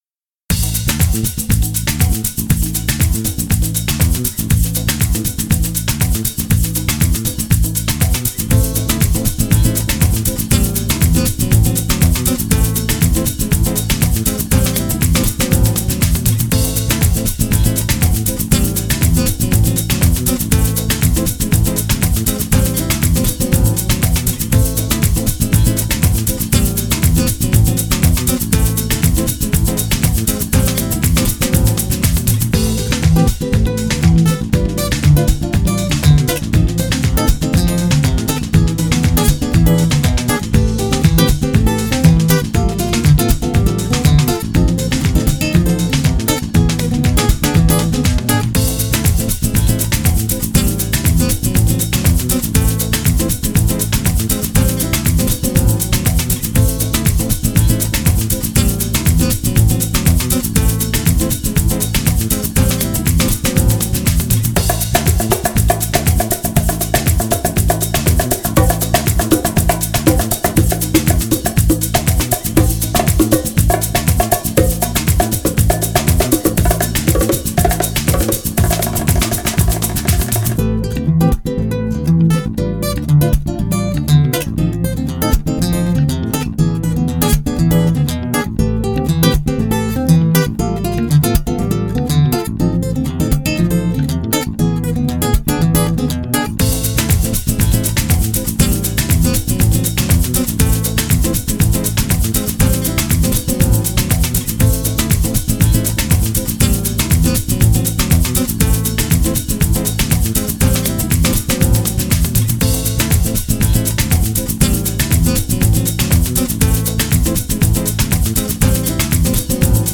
это яркая и энергичная композиция в жанре самба